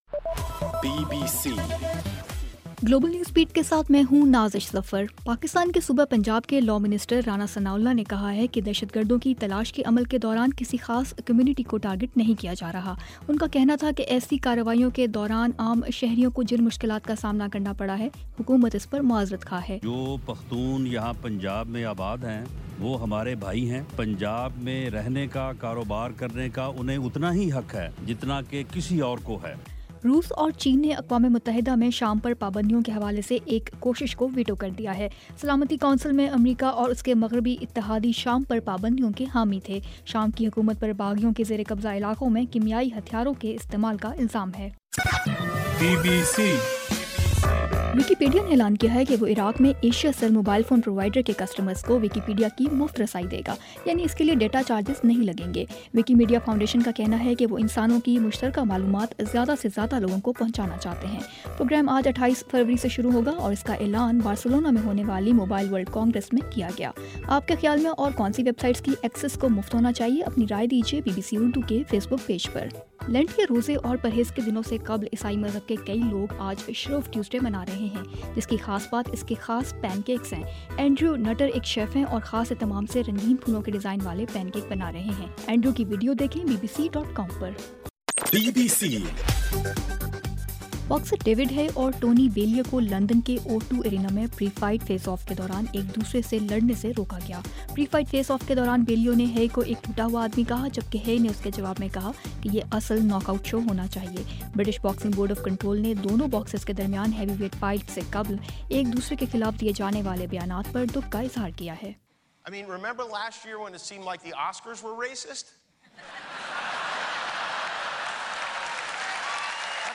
بُلیٹن